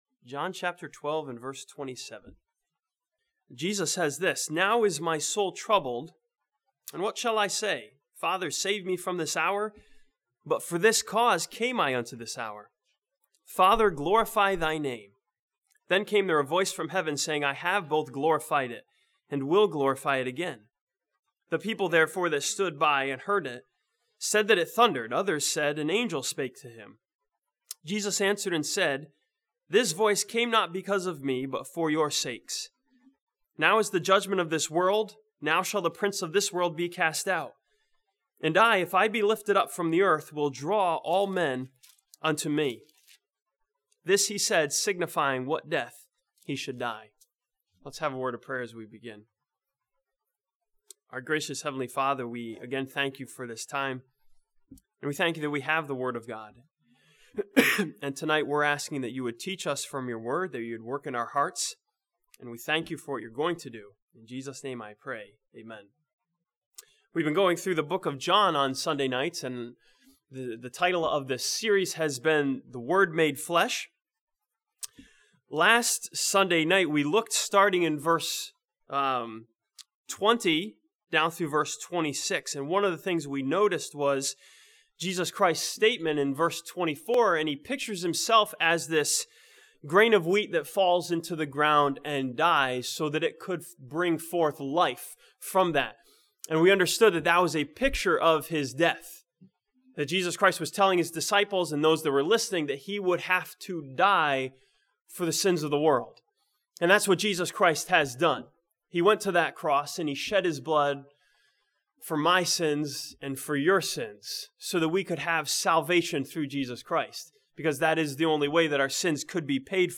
This sermon from John chapter twelve looks at the trouble Jesus faced and considers the answer to His question, "What shall I say?"